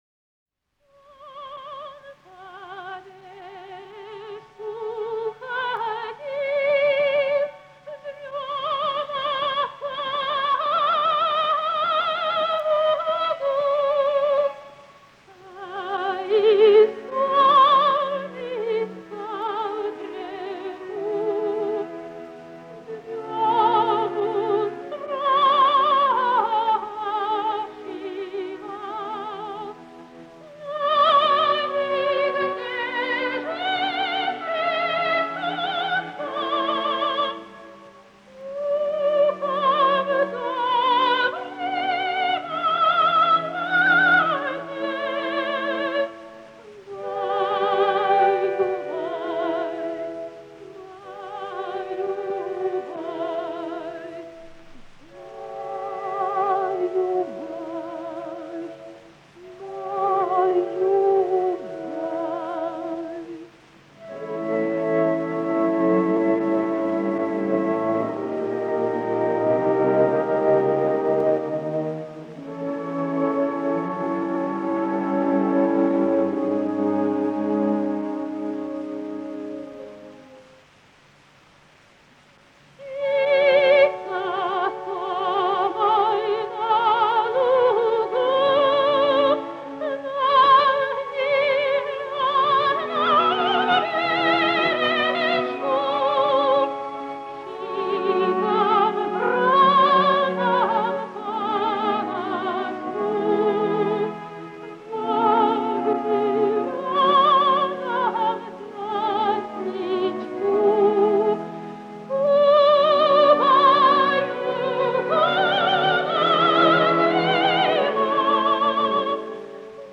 Жанр: Opera
В исполнении отечественных певиц.